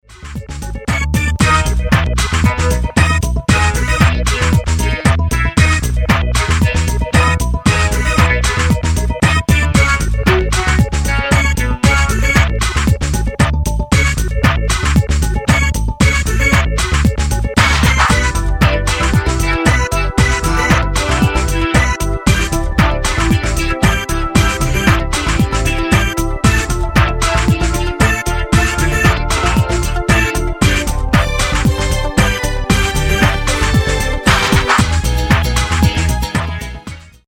ヴィンテージ・シンセサイザーやヴォコーダーを多用したマニアックなサウンドがキッチュなメロディーを彩る。